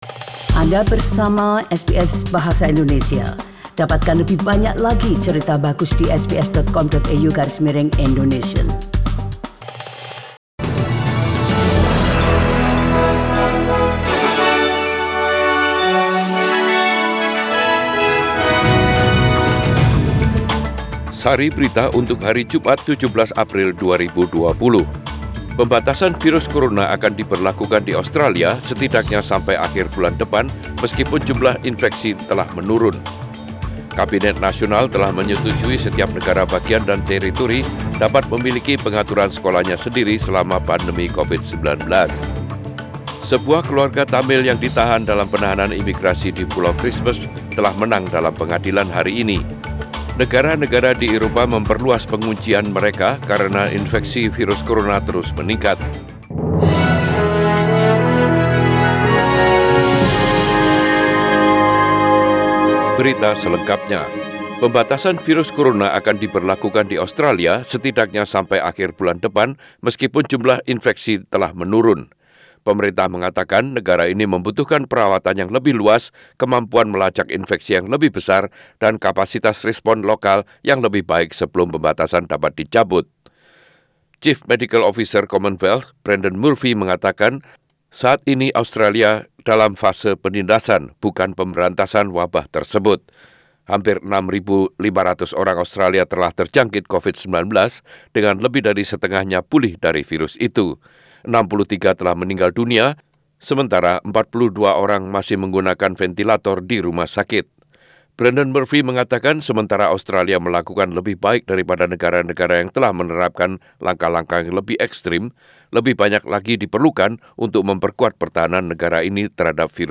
Warta Berita Radio SBS Proogram Bahasa Indonesia - 17 APril 2020